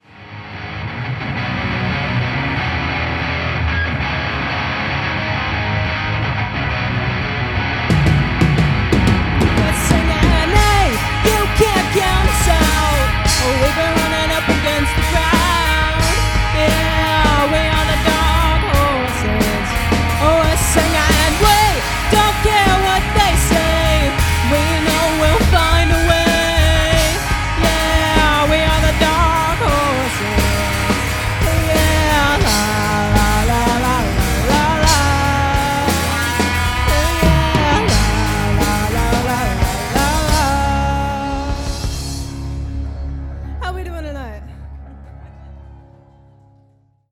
A hard hitting rock cover band
Lead Vocals
Lead Guitar, "Easter Eggs"
Bass Guitar, Occasional Vocals
Drums, Other Noises